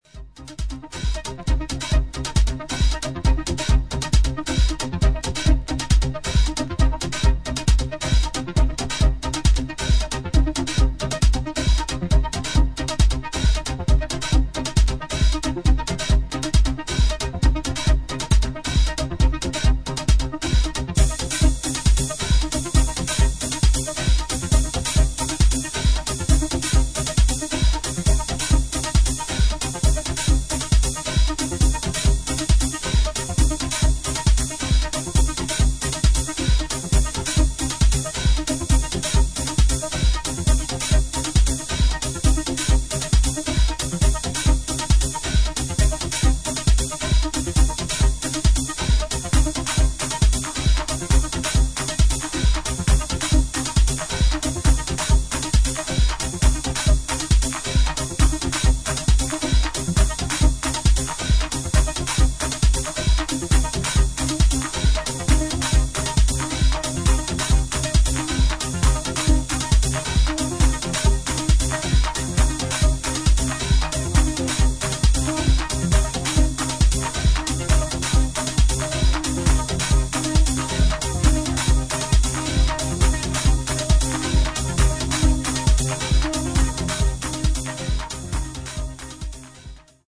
シンプルな編成ながら深みが感じられるエクスペリメンタル的要素が垣間見える90"テクノ傑作